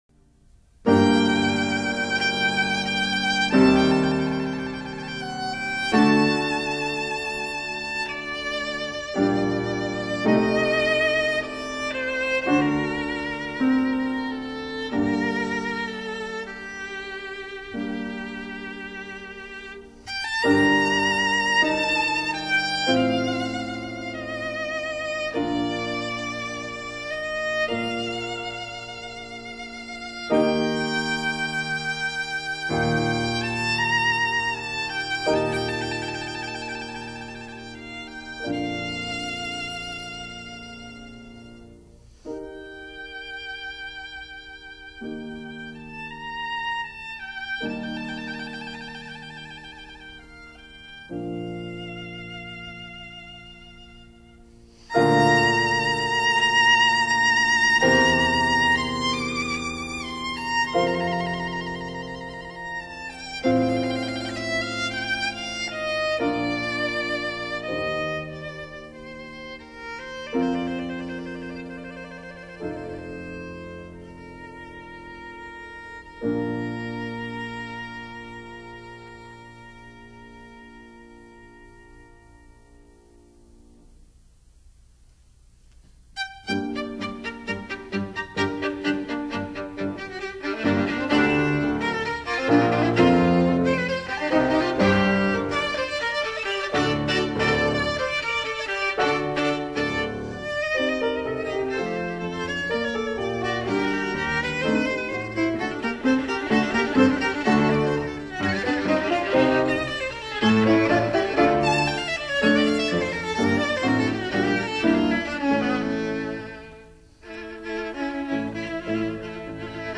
grave-allegro assai